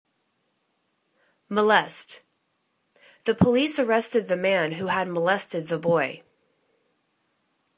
mo.lest     /mə'lest/    v